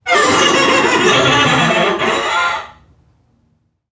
NPC_Creatures_Vocalisations_Robothead [45].wav